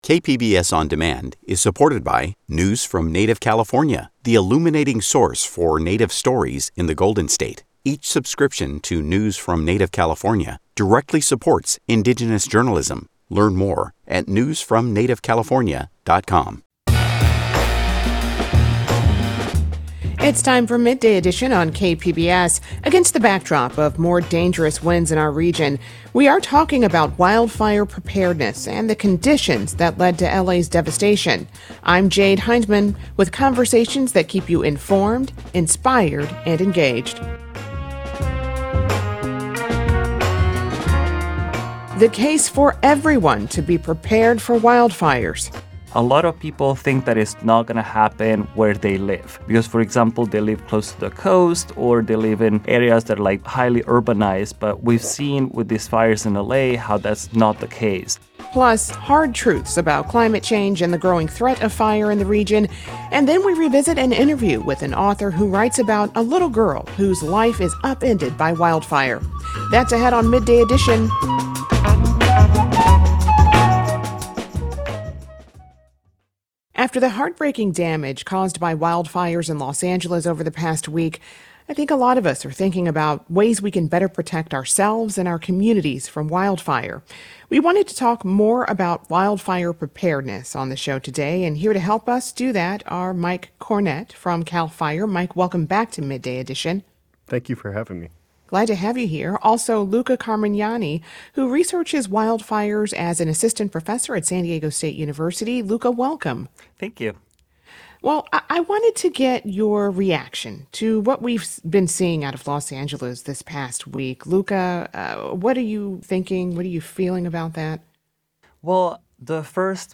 Guests share diverse perspectives from their expertise and lived experience.